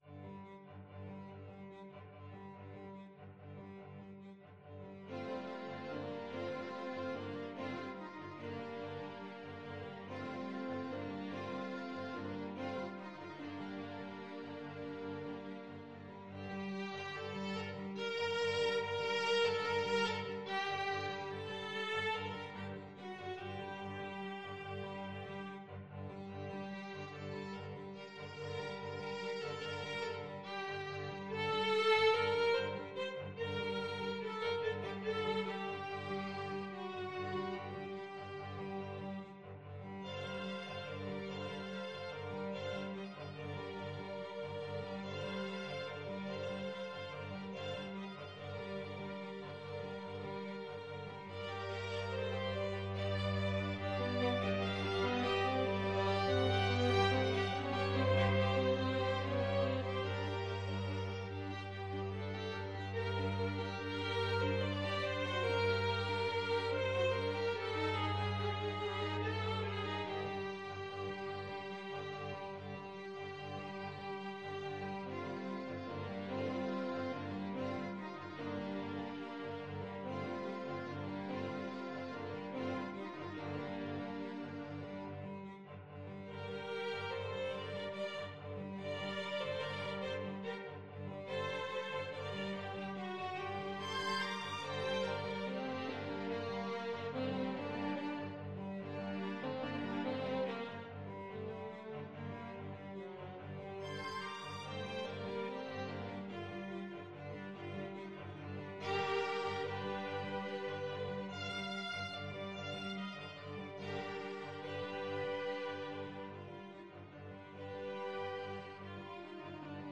Violin 1Violin 2ViolaCello
3/8 (View more 3/8 Music)
Commodo =144
Classical (View more Classical String Quartet Music)